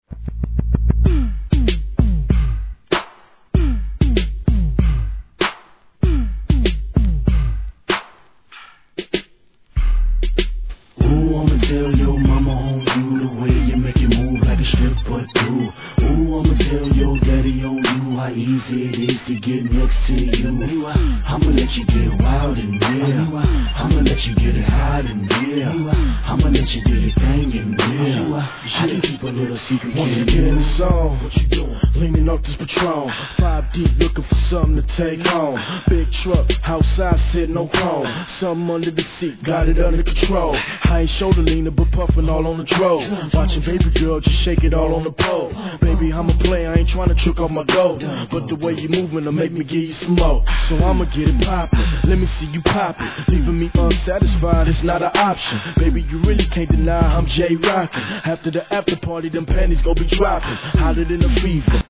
HIP HOP/R&B
シンプルなBERATにスペイシーな上音が中毒性大!